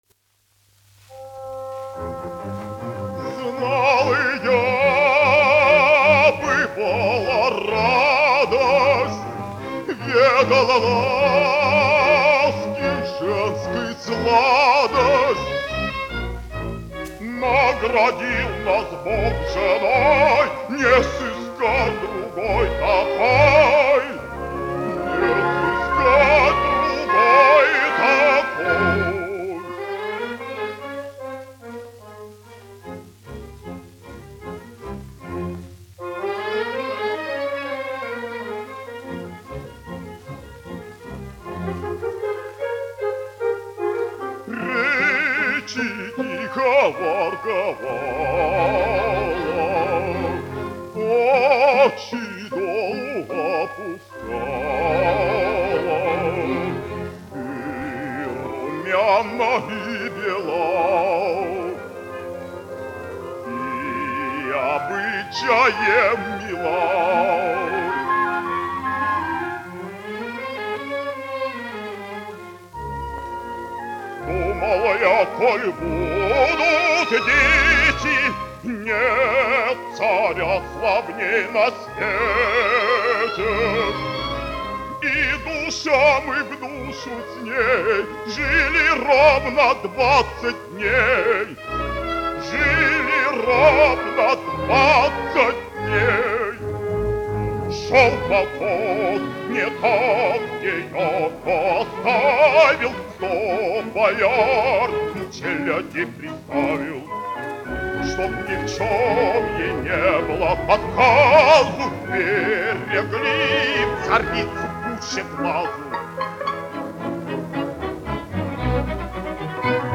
Пирогов, Александр, 1899-1964, dziedātājs
Мелик-Пашаев, Александр, (Александр Шамильевич), 1905-1964, diriģents
Большой театр СССР. Оркестр, izpildītājs
1 skpl. : analogs, 78 apgr/min, mono ; 25 cm
Operas--Fragmenti